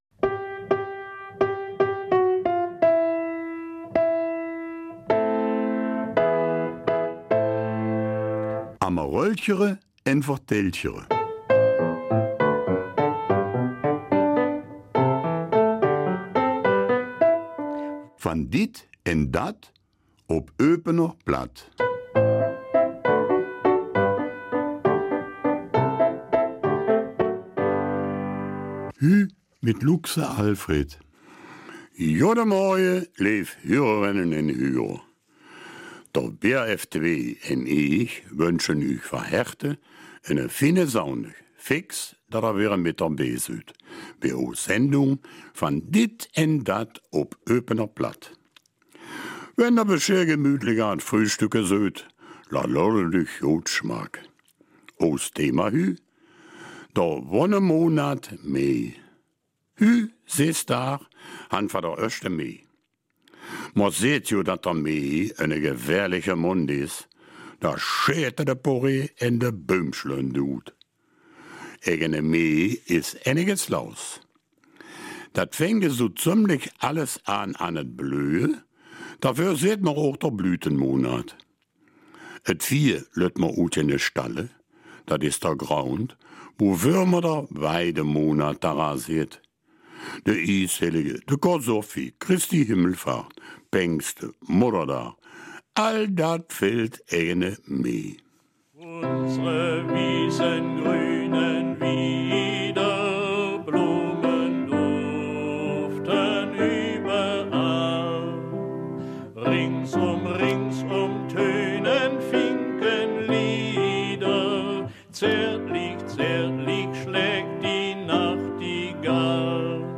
Eupener Mundart: Der Wonnemonat Mai